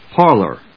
音節par・lor 発音記号・読み方
/pάɚlɚ(米国英語), pάːlə(英国英語)/